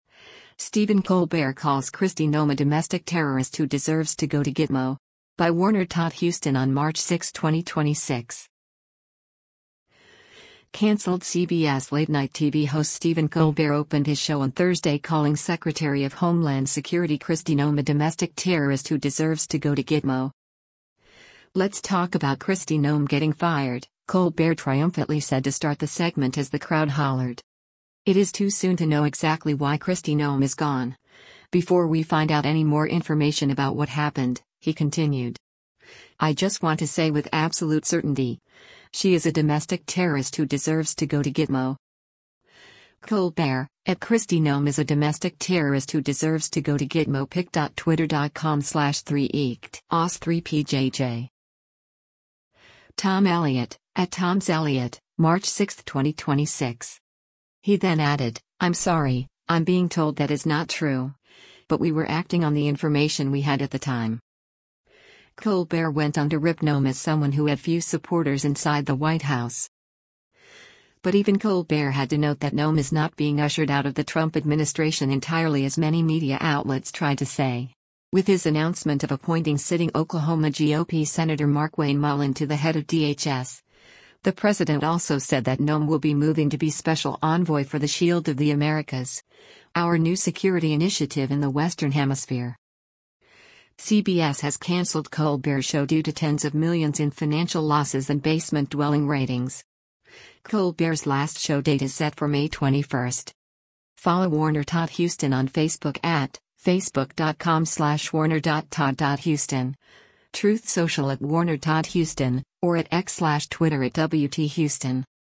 “Let’s talk about Kristi Noem getting fired,” Colbert triumphantly said to start the segment as the crowd hollered.